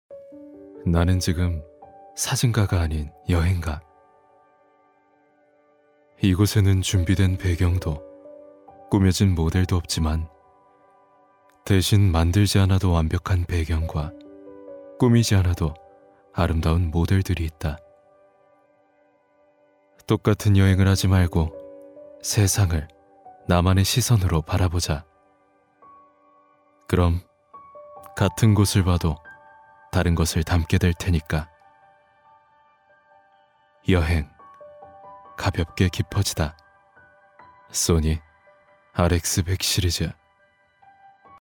성우샘플
차분/편안